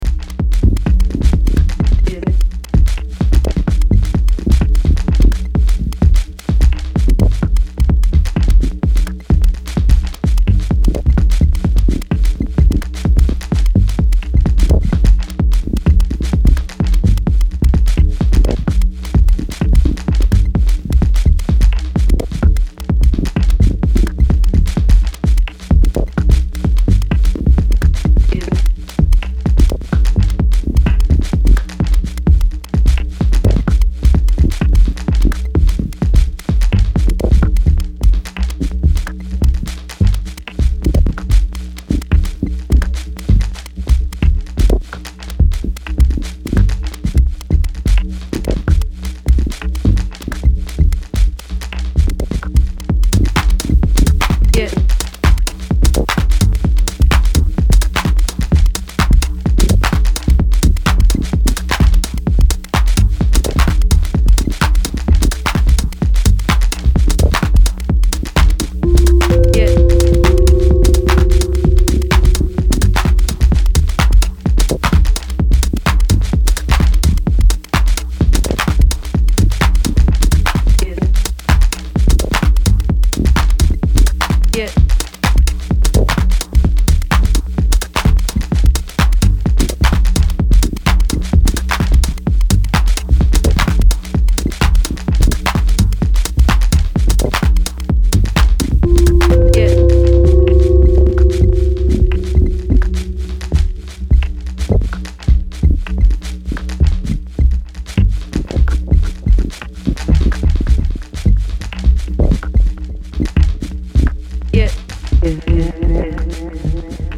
characterized by a slightly more mechanical, nuanced rhythm.
laidback mood and stripped down groove
lowend bassline, enigmatic synth line and vocal cuts